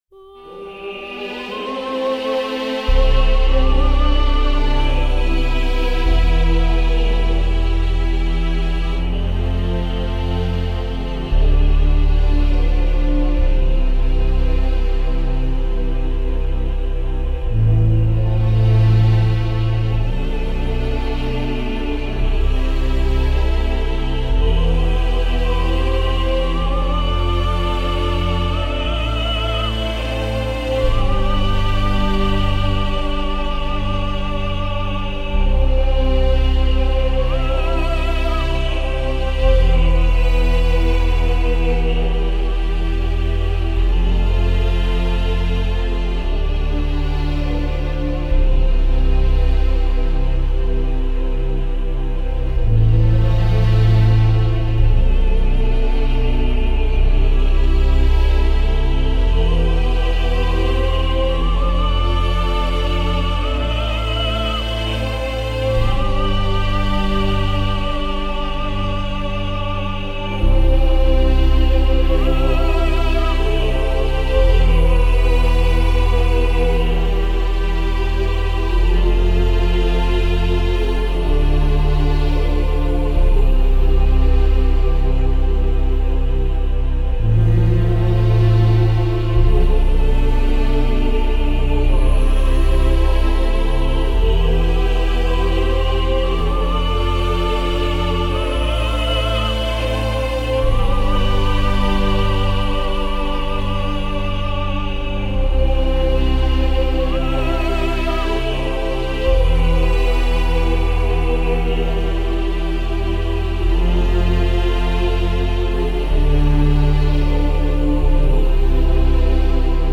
*Updated MP3 file: Soundtrack Mastered and balanced, sounds better now
Singer from East West sounds online digitalized, but played with my midi controler